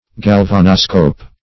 Search Result for " galvanoscope" : The Collaborative International Dictionary of English v.0.48: Galvanoscope \Gal*van`o*scope\, n. [Galvanic + -scope: cf. F. galvanoscope.]